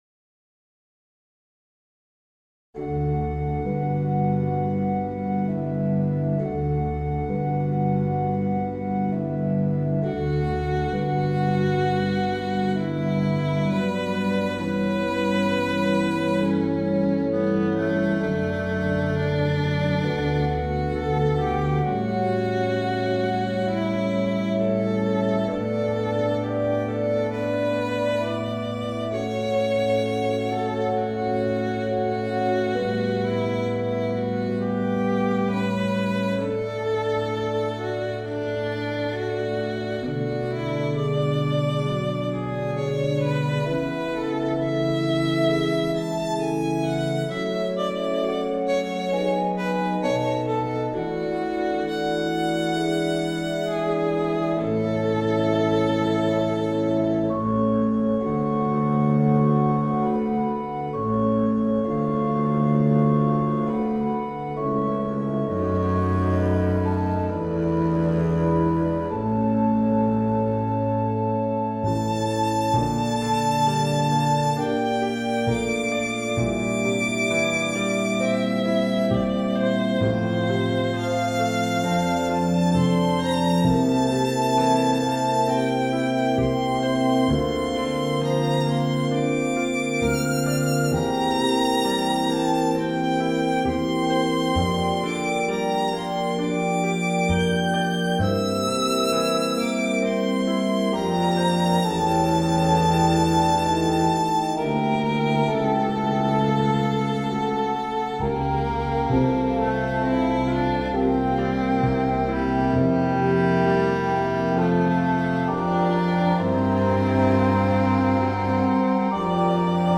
Samuel Rousseau, Méditation pour violon avec accompagnement d'orgue, harpe ou piano et contrebasse ad libitum